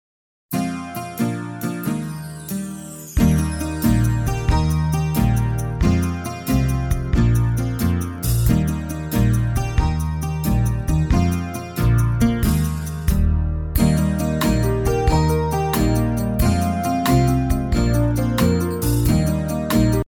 Listen to a sample of the BONUS instrumental track.